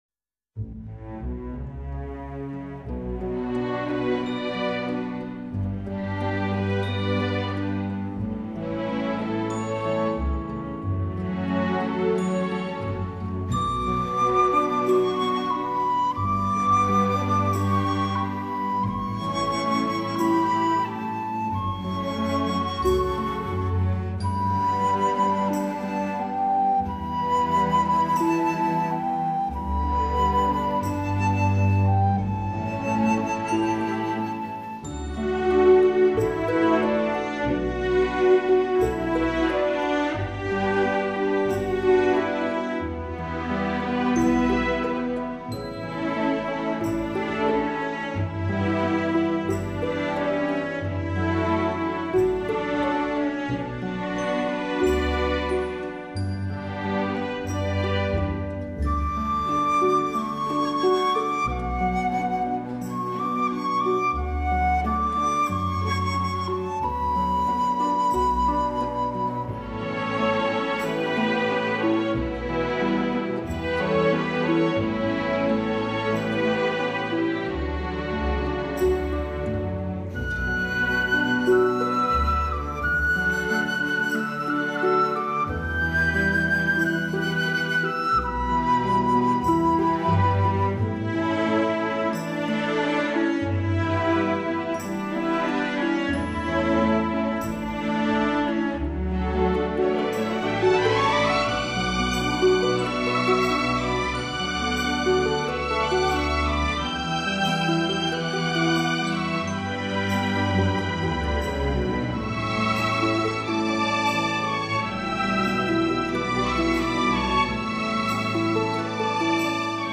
音乐类型：New  age
首张以传统笛子与排箫演绎中、德、日、法、希腊、挪威等全球顶尖作曲家畅销曲目。